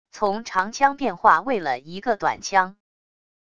从长枪变化为了一个短枪wav音频